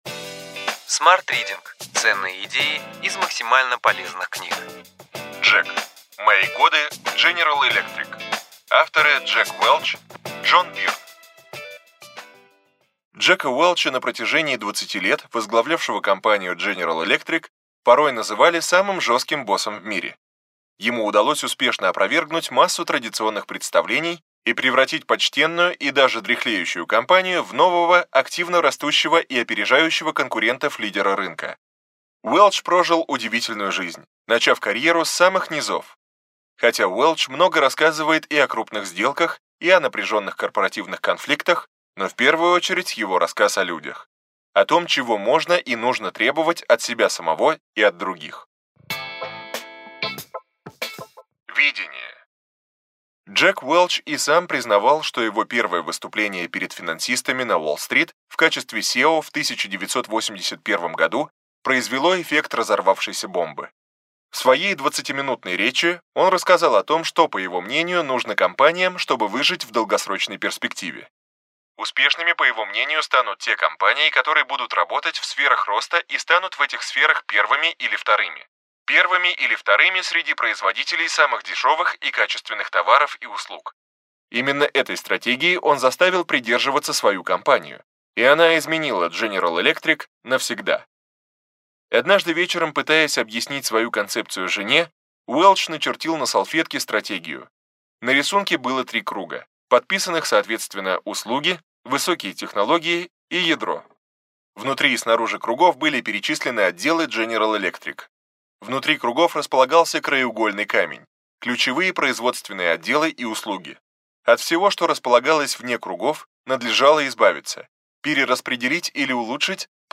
Аудиокнига Ключевые идеи книги: Джек. Мои годы в GE.